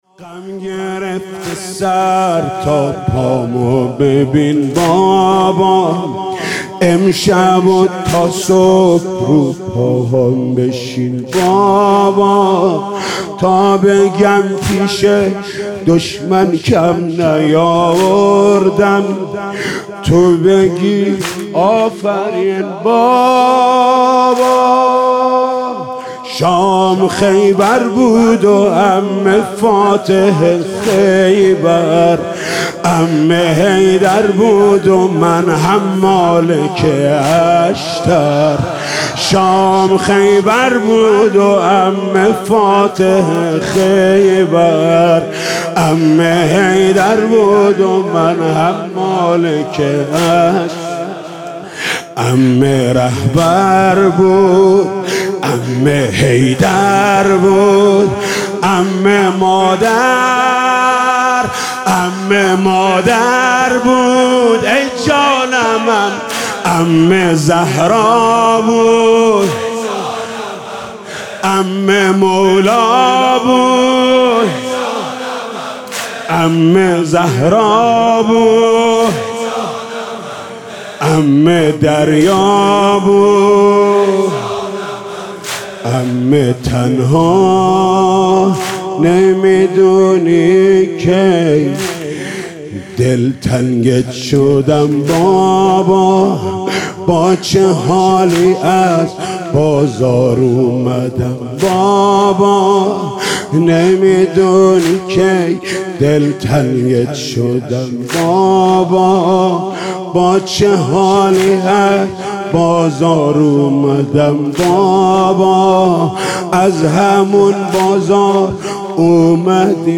محرم 97 شب سوم